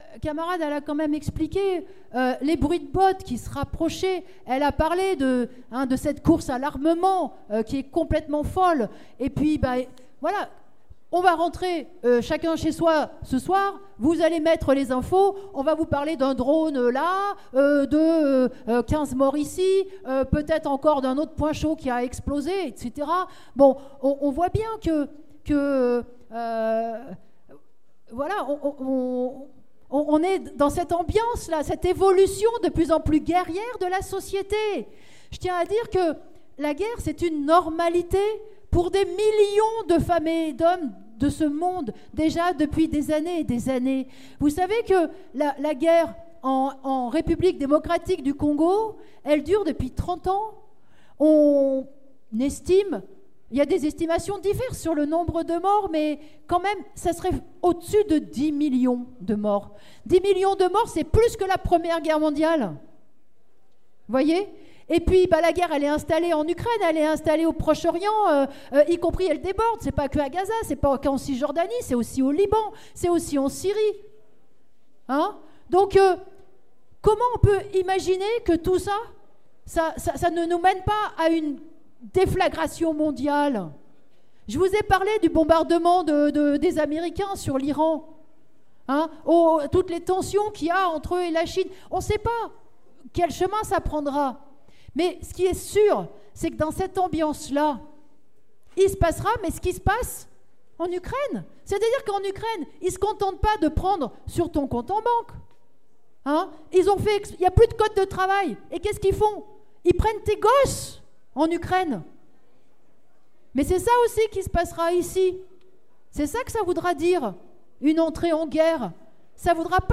Nathalie Arthaud débat à la fête lyonnaise de LO : L'évolution réactionnaire de la société nous conduit à la guerre